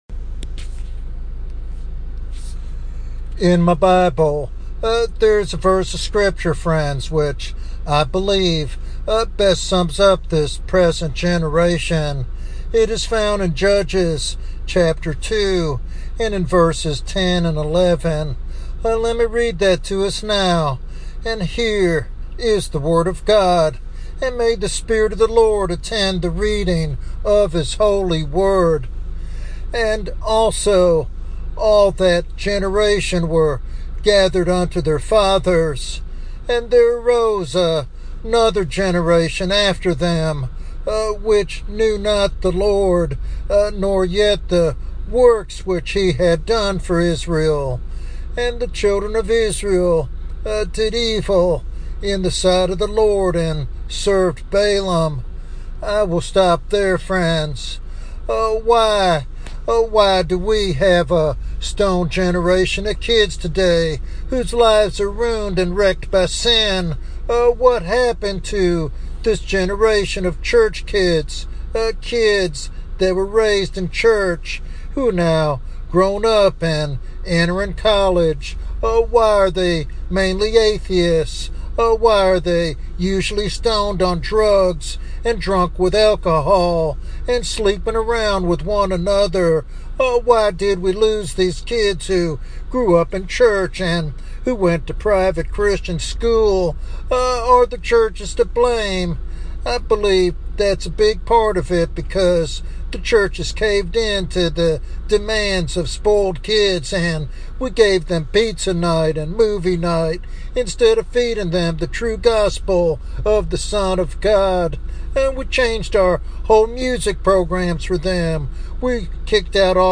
This sermon is a passionate plea for the church to awaken and reclaim its spiritual responsibility.